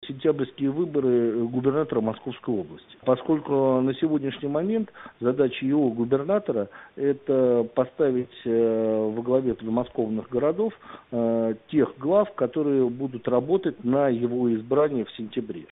Политтехнолог